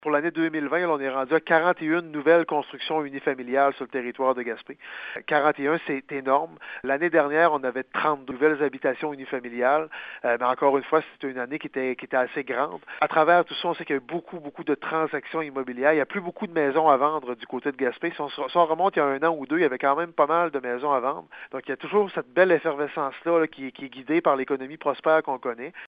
Enfin, le maire de Gaspé souligne aussi l’effervescence entourant les nouvelles constructions résidentielles sur le territoire de Gaspé ainsi que le nombre de ventes de maisons en hausse en 2020 :